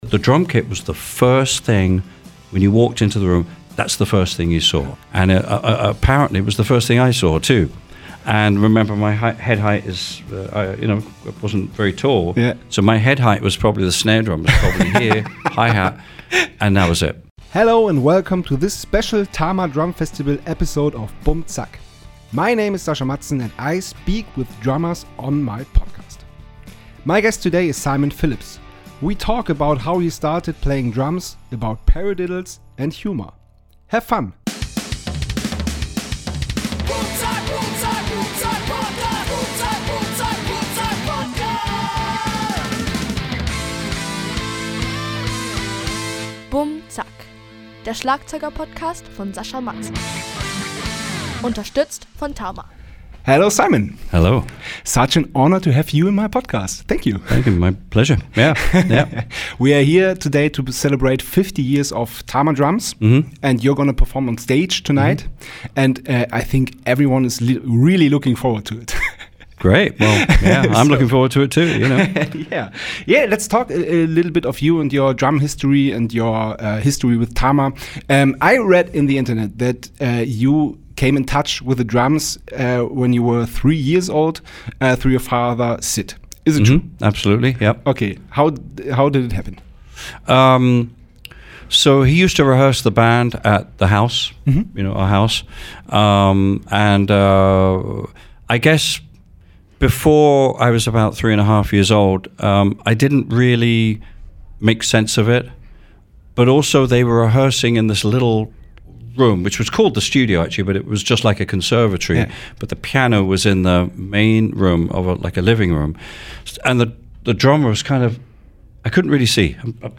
Letzte Episode 98 – Simon Phillips 4. Juni 2024 Nächste Episode download Beschreibung Teilen Abonnieren Hello and welcome to this special Tama Drumfestival episode of BummZack.
My guest today is Simon Phillips. We talk about how he started playing drums, about paradiddles and humor.